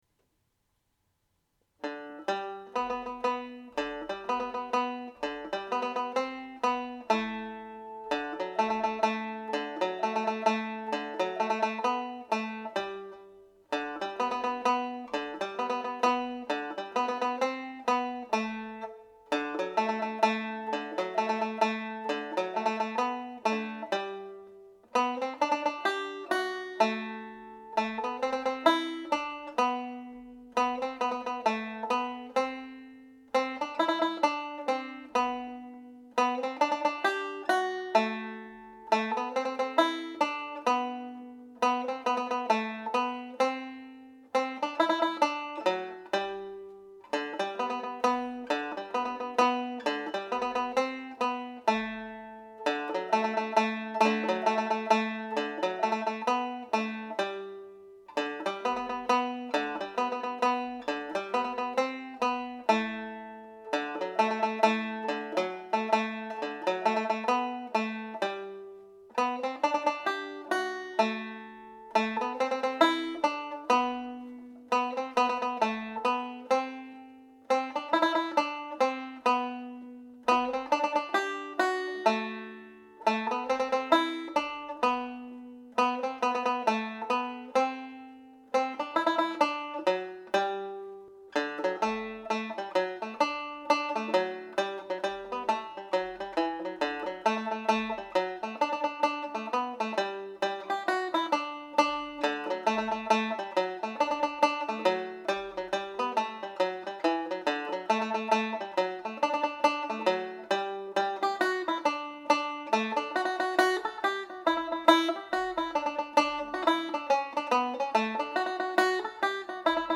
Shoe the Donkey set played with Sonny’s mazurka